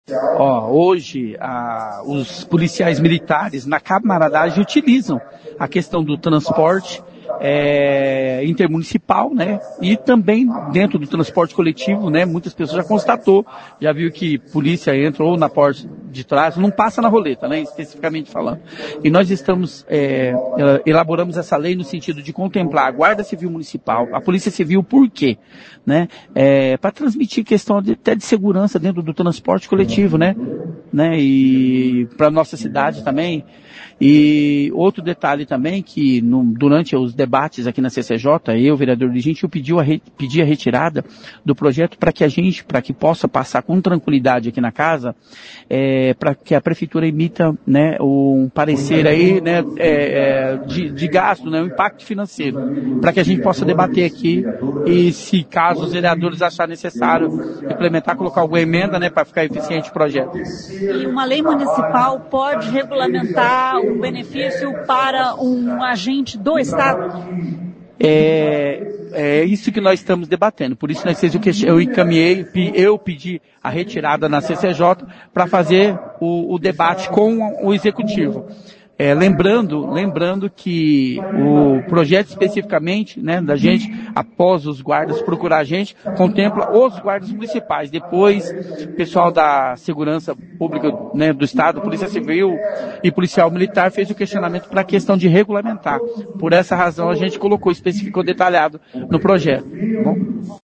Um projeto de lei que tramita na Câmara Municipal de Maringá propõe que guardas civis municipais e policiais civis possam utilizar ônibus do transporte coletivo para ir e voltar do trabalho sem pagar. O projeto é do vereador William Gentil, mas depende de impacto financeiro para continuar tramitando. Ouça o que diz o vereador: